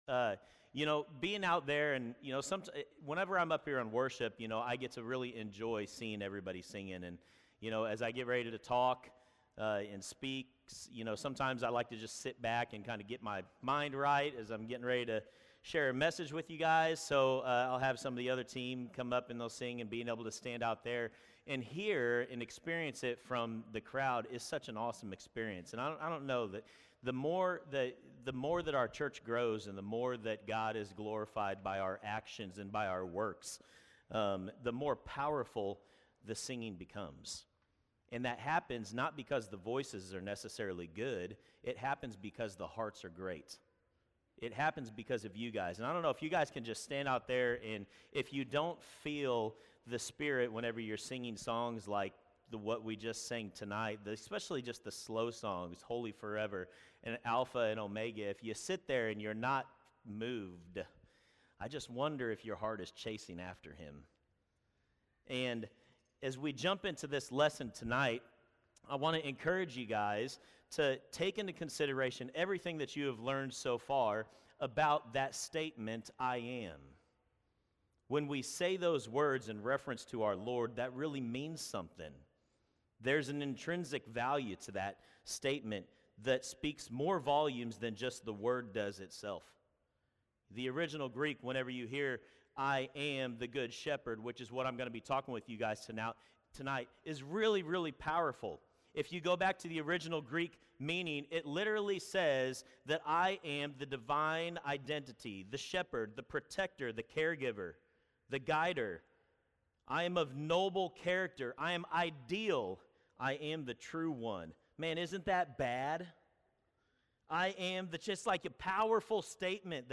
Series: "I AM" Refresh Retreat 2026